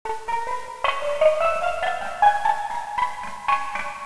Steel Drums
12" Double Tenors !
Range: A4 - D5